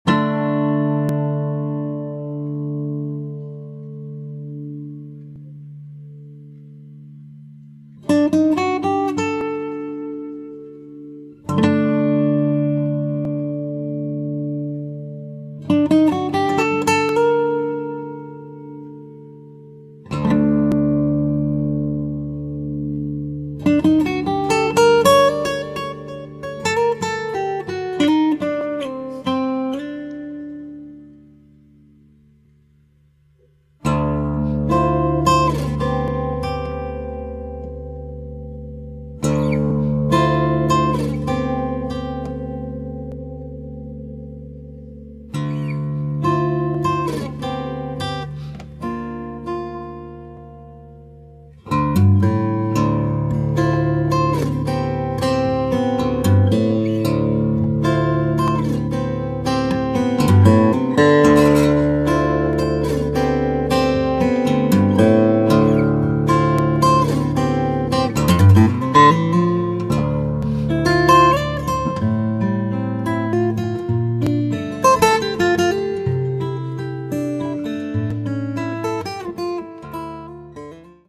chitarra, armonica
è il più armonicamente complesso di tutto il CD.